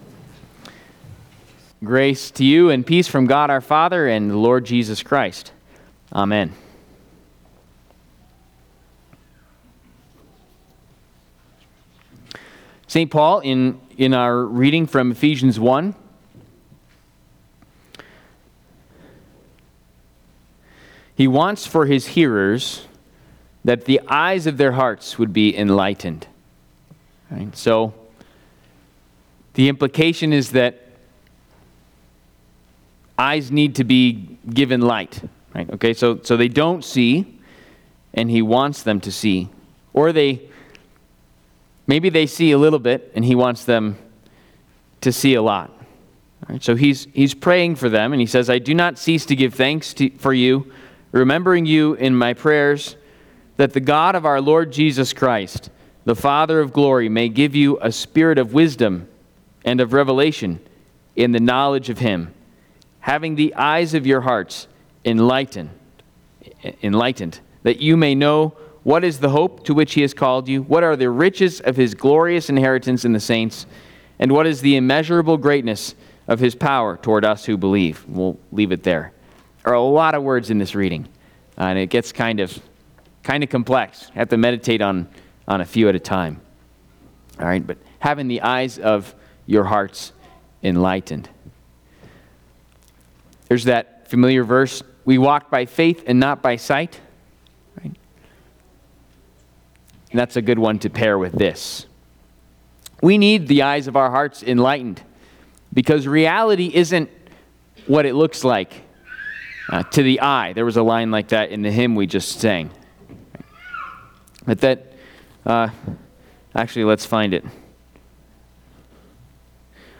The Ascension of Our Lord&nbsp